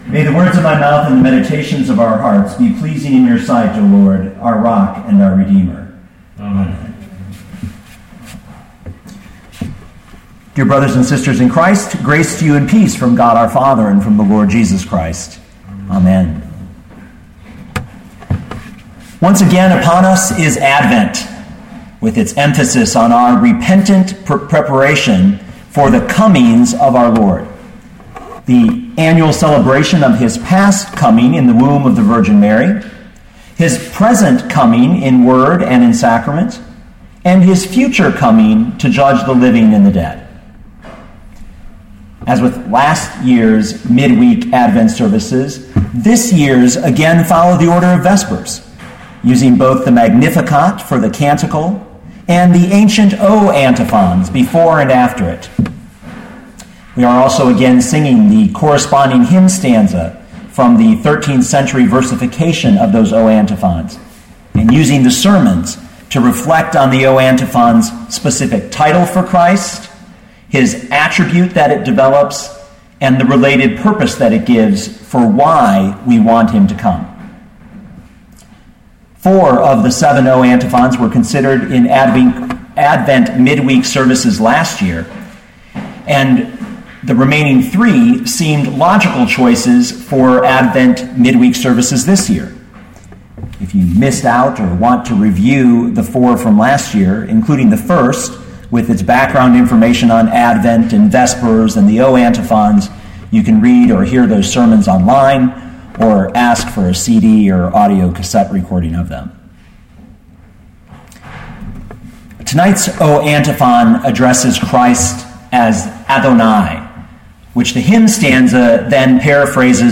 John 5:19-29 Listen to the sermon with the player below